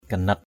/ɡ͡ɣa-nat/ (cv.) ginat g{qT (d.) bóng vía. soul, spirit. laman ganat lMN gqT yếu bóng vía.